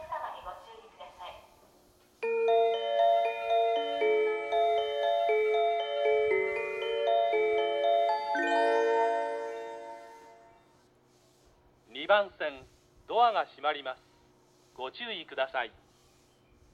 立川・東京方面   接近放送(PRC型)
発車メロディー(朝の静けさ)   夜間音量での収録です。